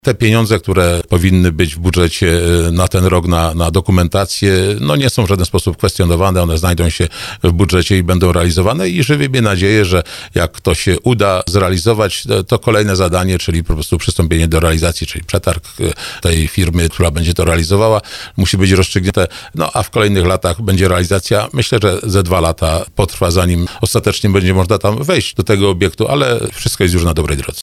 Wicemarszałek Województwa Małopolskiego Ryszard Pagacz przekonywał na antenie Radia RDN Małopolska, że kwota 300 tys. zł na ten cel jest już wpisana do wojewódzkiego budżetu.